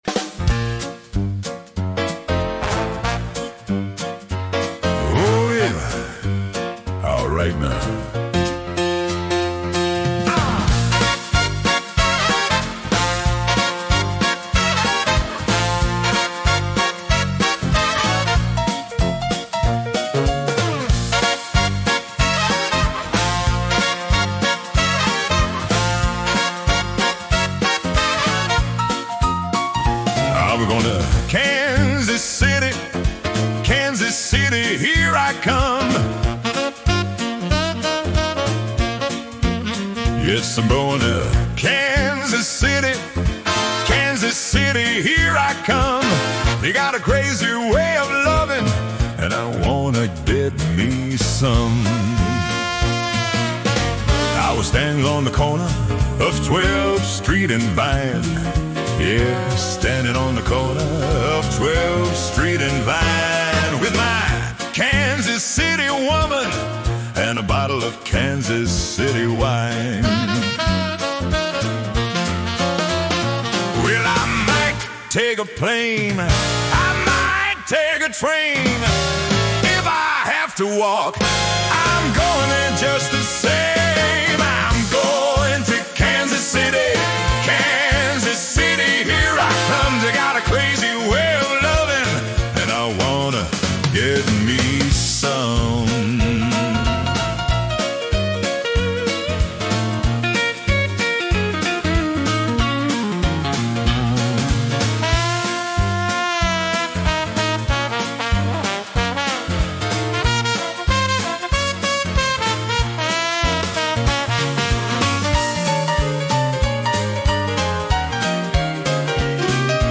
Canciones de muestra con voces clonadas